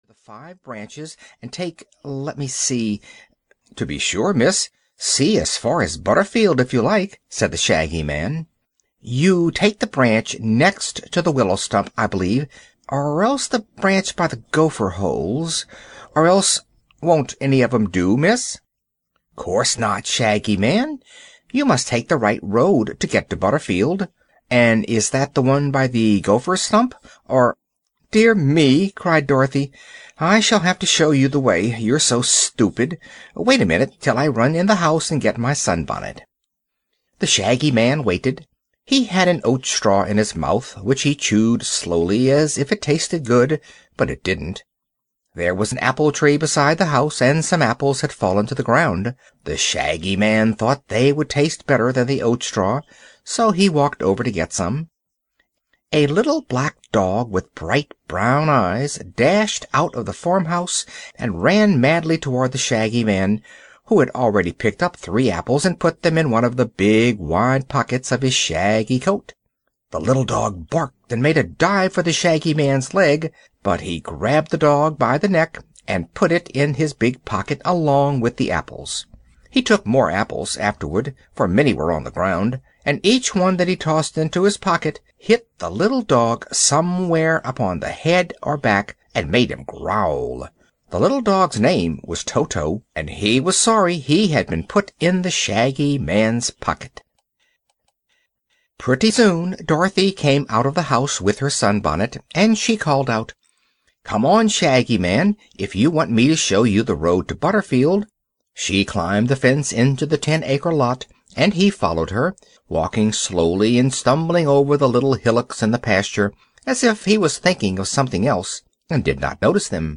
The Road to Oz (EN) audiokniha
Ukázka z knihy